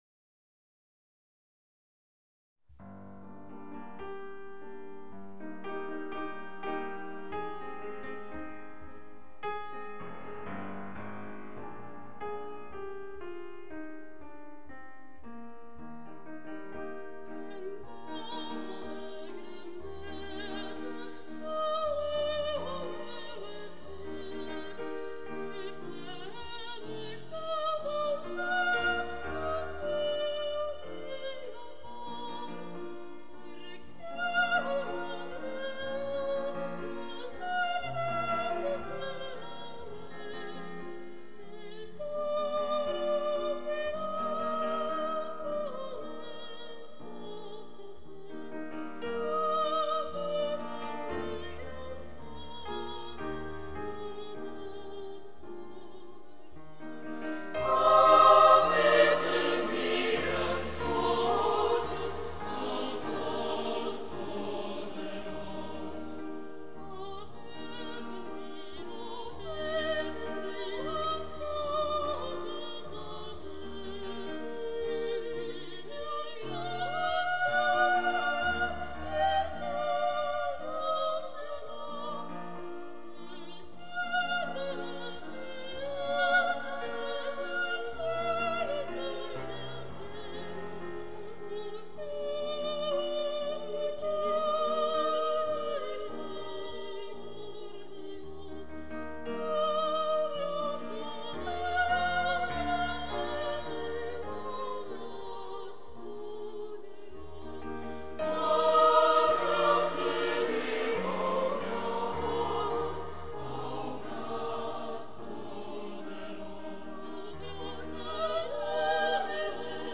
Orgue
Католический хор мальчиков